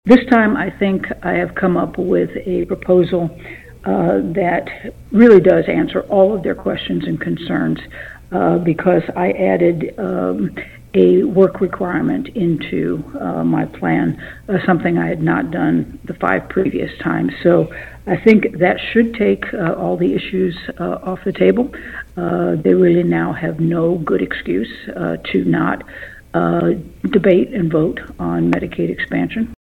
Kansas Governor Laura Kelly discussed several key topics in the Statehouse during an interview airing Wednesday on KVOE’s Newsmaker segment.